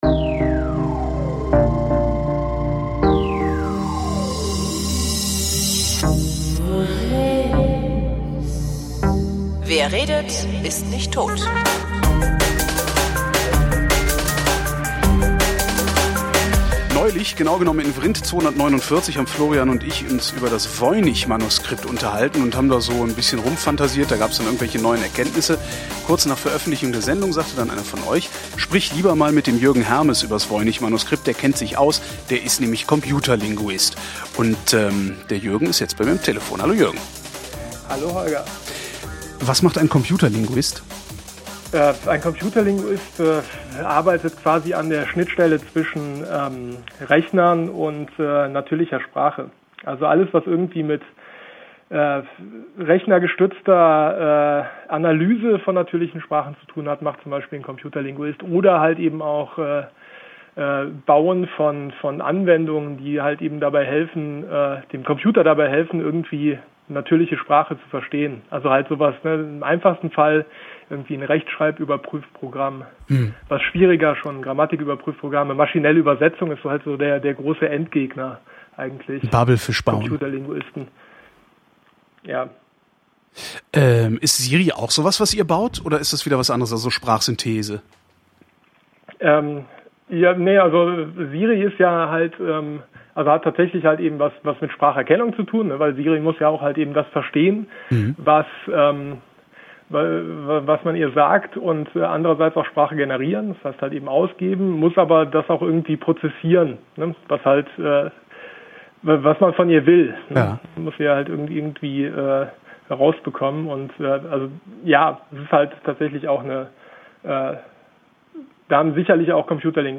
wrint: gespräche zum runterladen